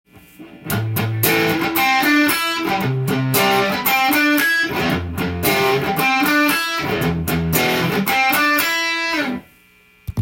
パワーコードとカッティング練習tab譜
ロックで少しファンキーな雰囲気で弾くことが出来ます。
低音が聞いたロックなパワーコードを弾いたら
８分音符でAmペンタトニックスケールを３つ弾いていきます。